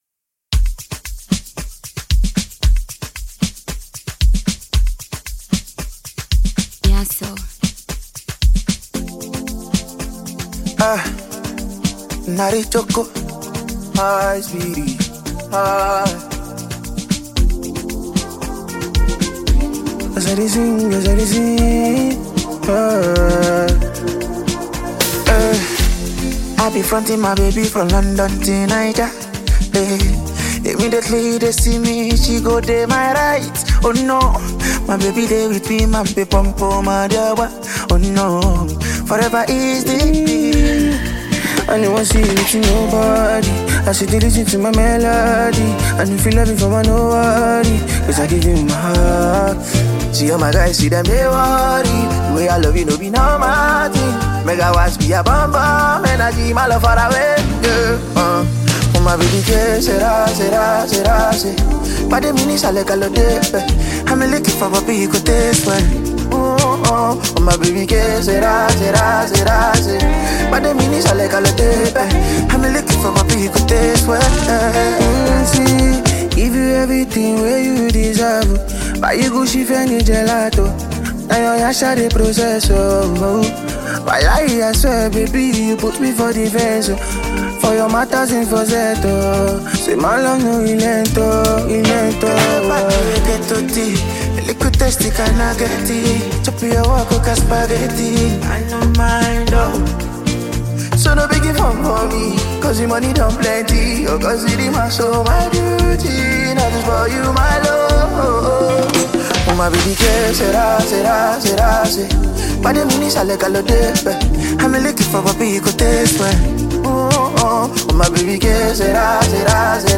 a skilled Nigerian singer